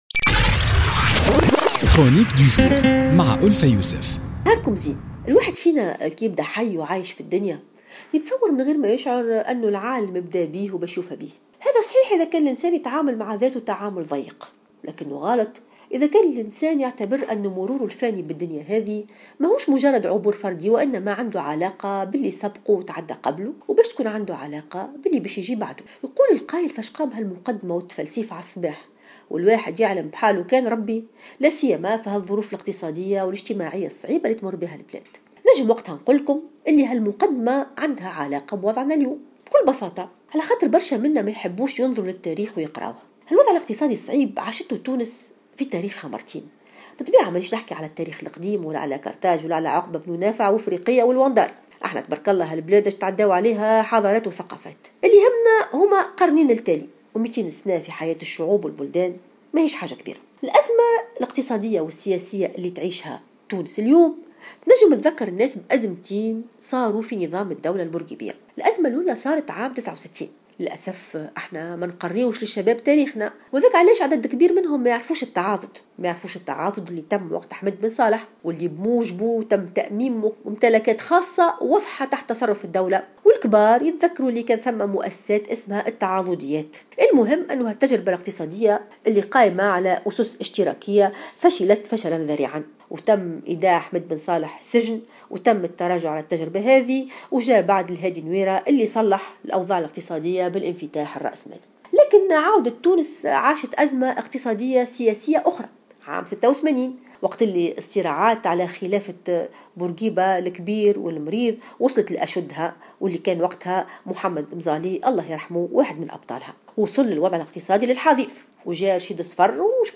اعتبرت الكاتبة ألفة يوسف في افتتاحيتها لـ "الجوهرة أف أم" اليوم الاثنين أن الوضع الاقتصادي الكارثي في تونس مشابه للوضع الذي عرفته تونس في القرن 19 إبّان فترة مصطفى خزندار.